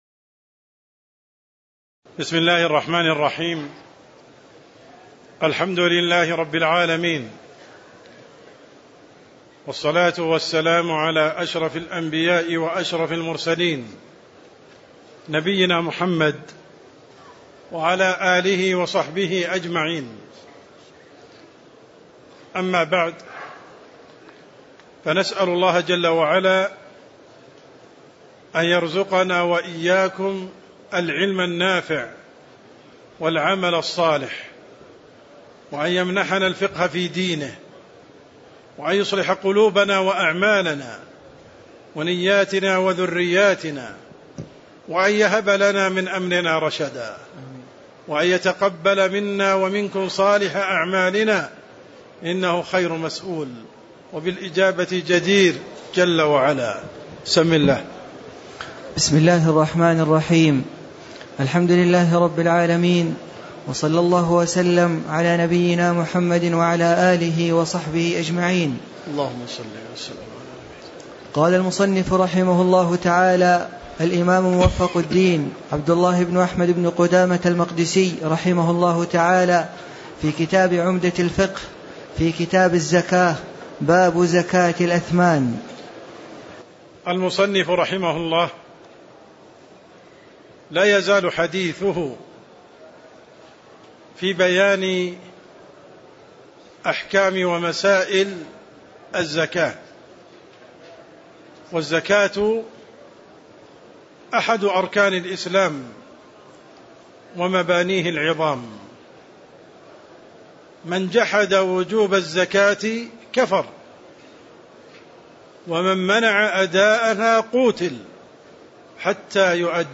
تاريخ النشر ٣٠ ذو القعدة ١٤٣٨ هـ المكان: المسجد النبوي الشيخ: عبدالرحمن السند عبدالرحمن السند باب زكاة الأثمان (03)